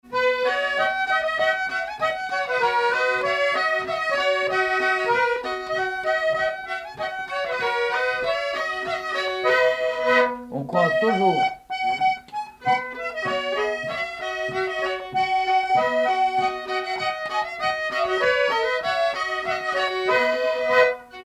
Marche
danse : marche
circonstance : bal, dancerie
Pièce musicale inédite